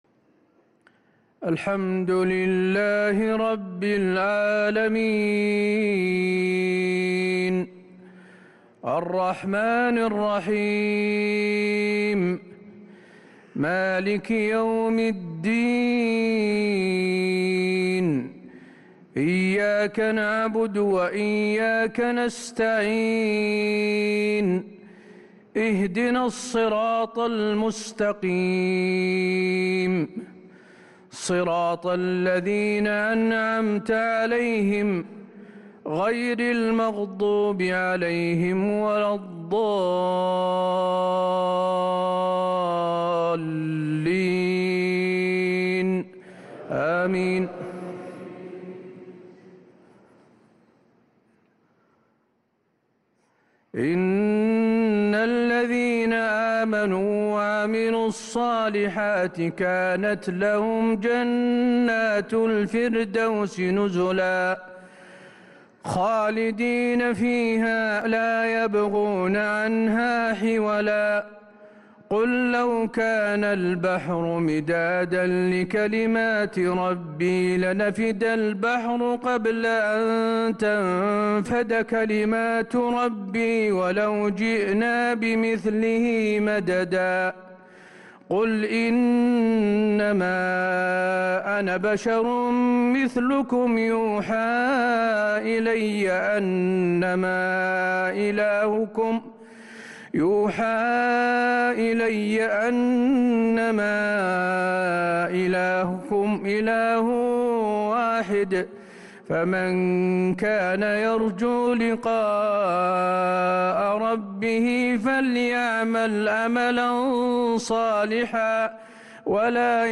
صلاة المغرب للقارئ حسين آل الشيخ 23 ذو الحجة 1443 هـ